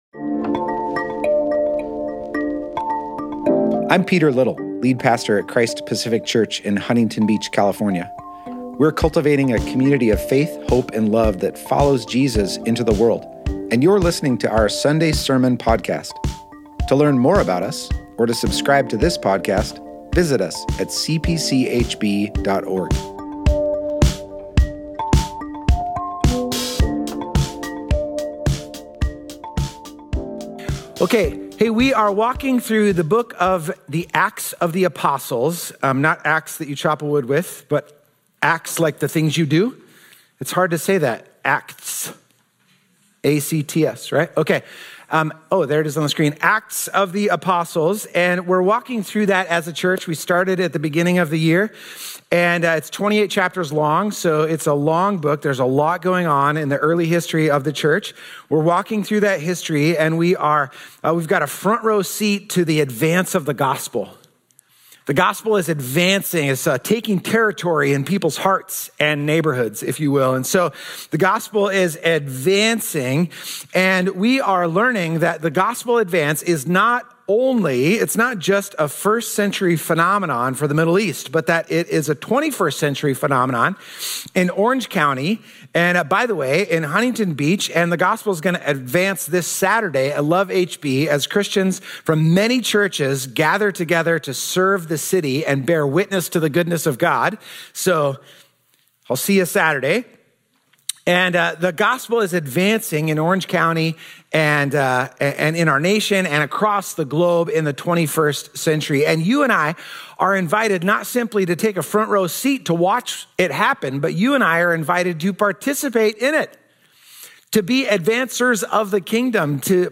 Thank you for joining us this morning we will continue in our current sermon series, Advance.